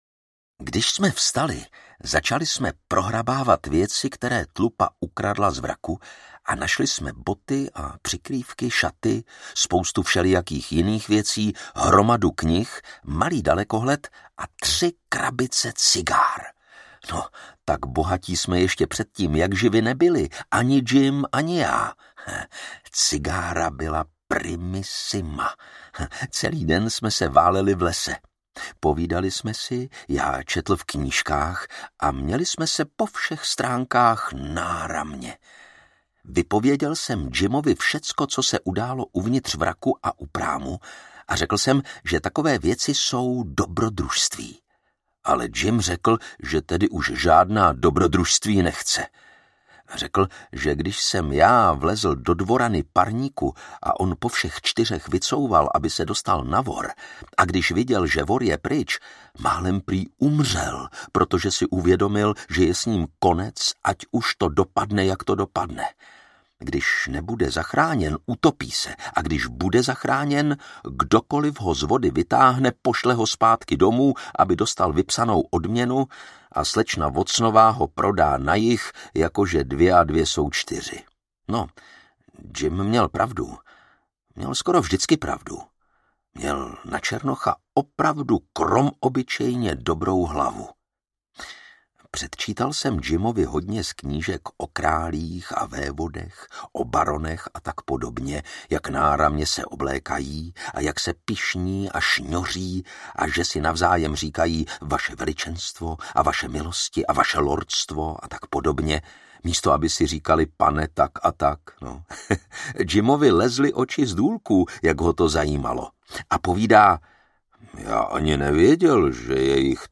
Dobrodružství Huckleberryho Finna audiokniha
Ukázka z knihy